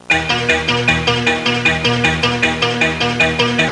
Hi Tech Intro Sound Effect
Download a high-quality hi tech intro sound effect.
hi-tech-intro.mp3